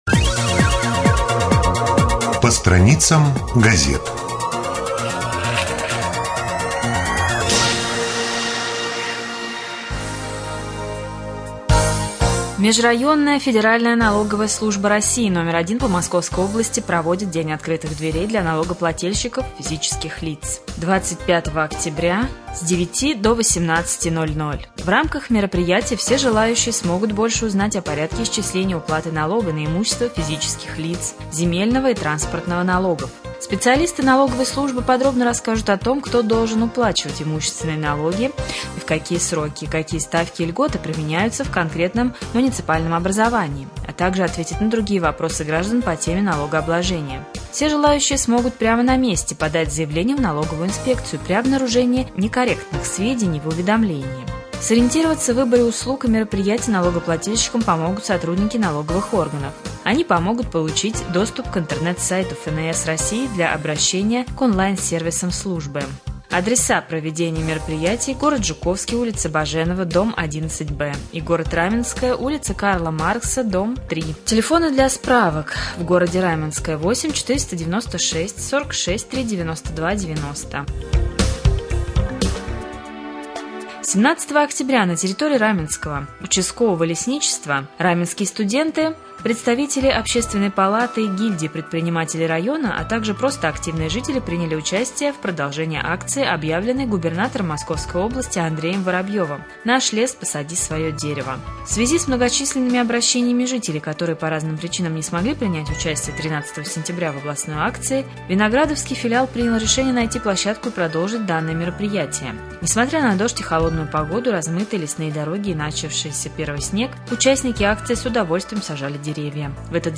1.Рубрика «По страницам прессы». Новости